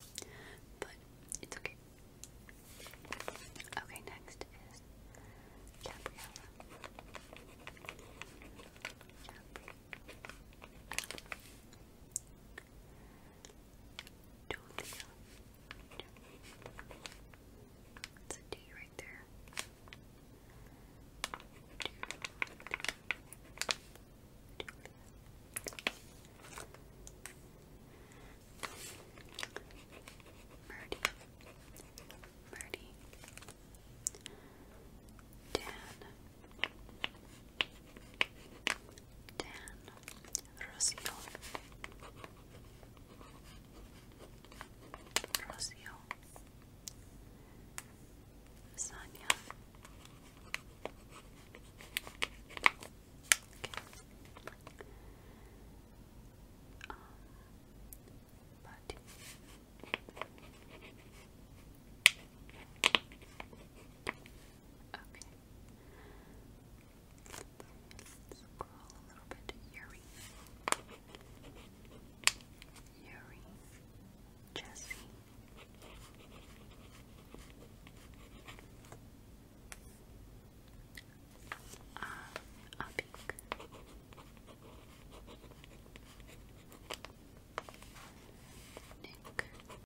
ASMR Writing your names on sound effects free download